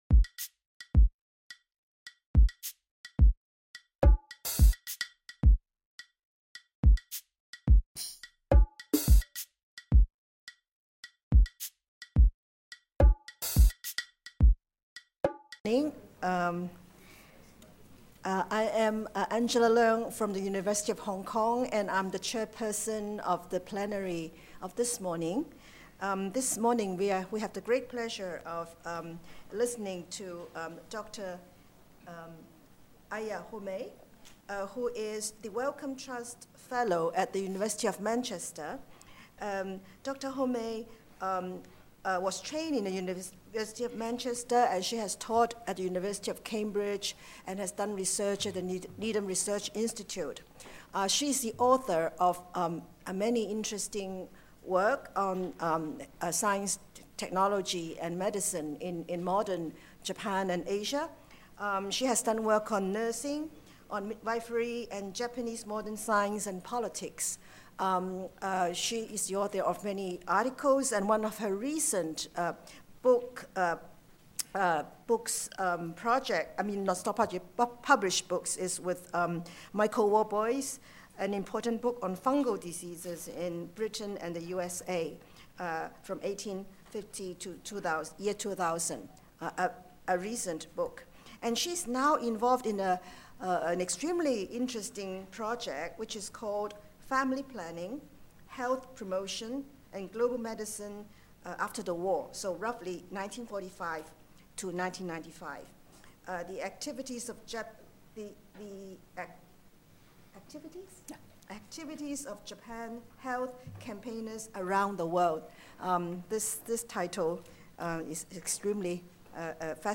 Plenary Lecture Chair